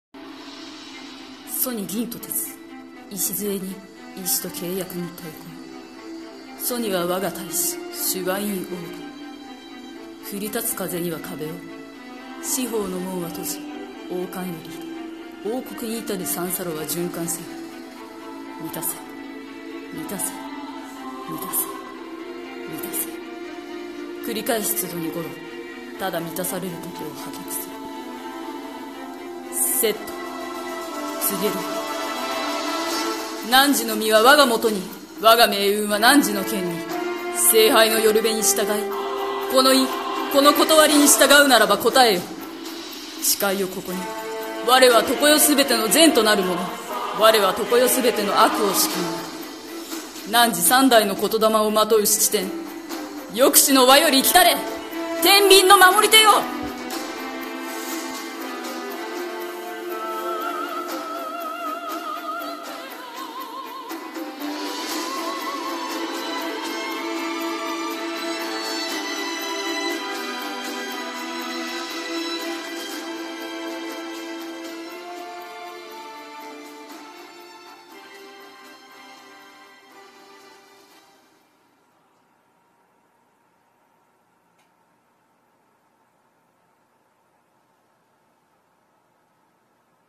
Let's 英霊召喚 【声劇